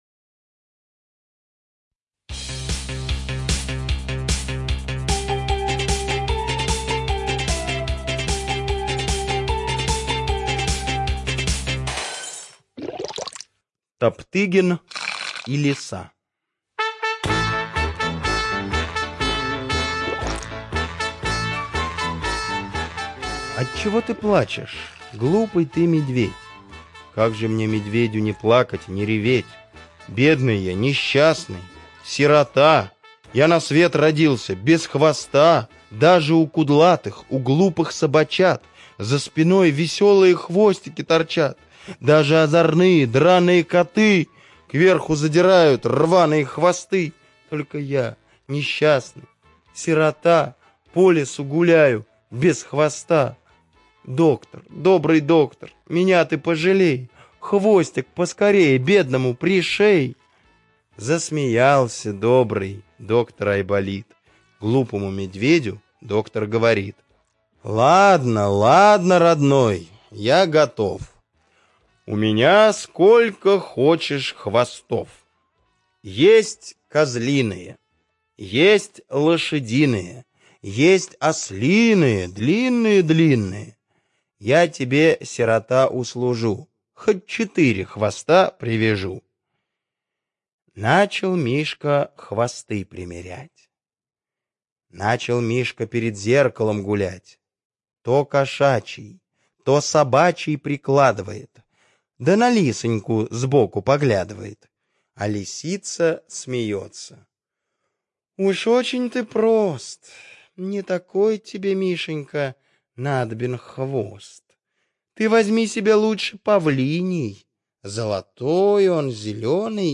admin 27.03.2022 Аудистихотворение Чуковского К.И. Слушайте аудиостихотворение «Топтыгин и лиса» Чуковского К.И. на сайте Минисказка!